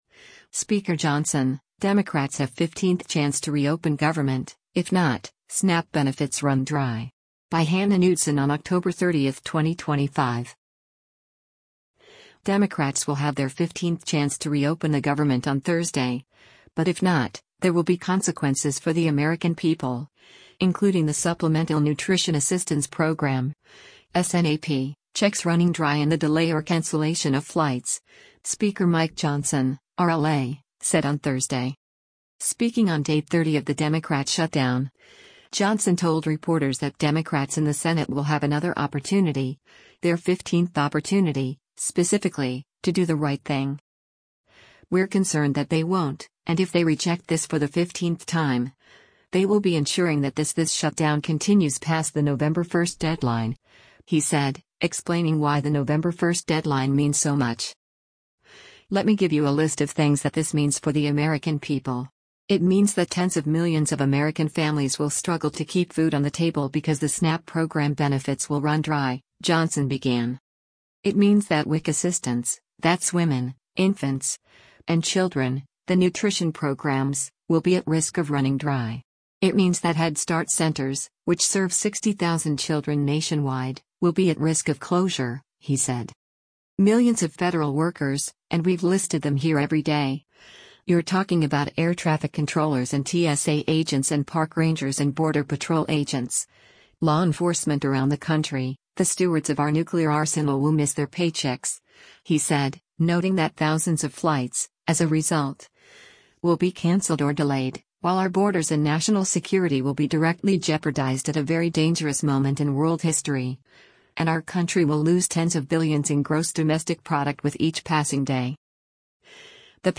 Speaking on day 30 of the Democrat shutdown, Johnson told reporters that Democrats in the Senate will have another opportunity – their 15th opportunity, specifically – to “do the right thing.”